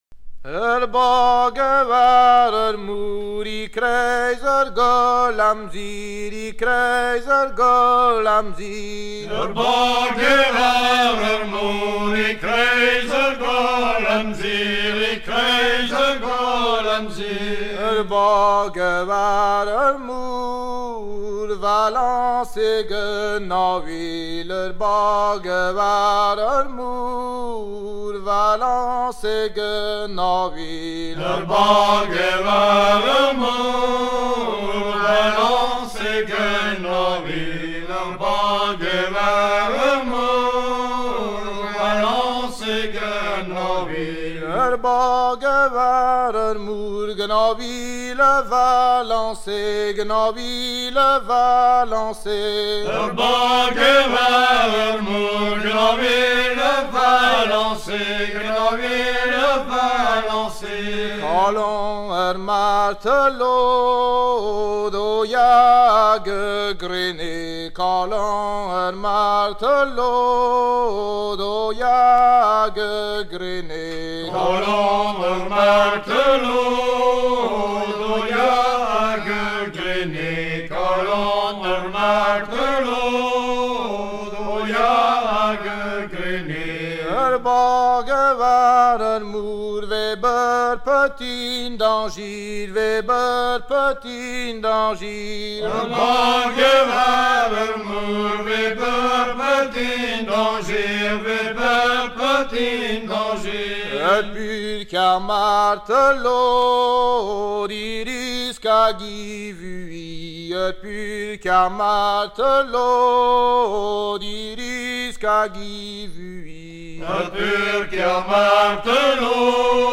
Version recueiliie dans les années 1970
Genre strophique
Pièce musicale éditée